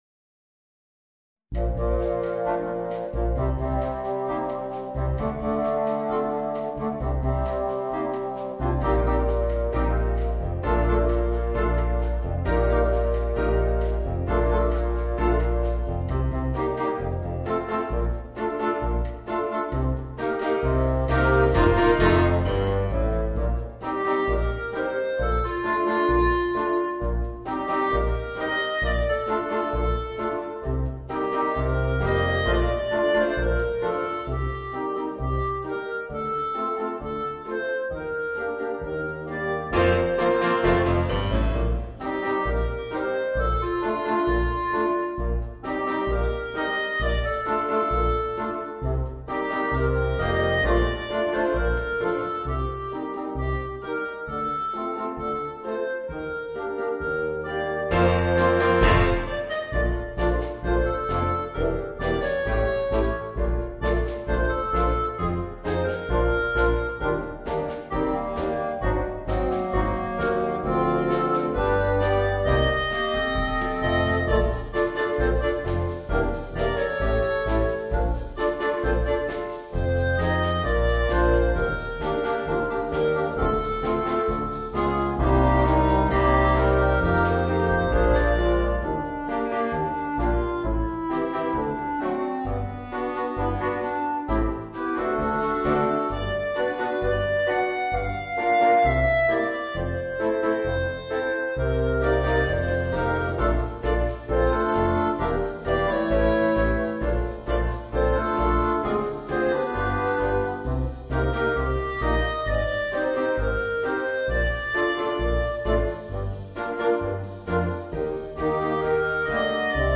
Voicing: Woodwind Quintet and Rhythm Section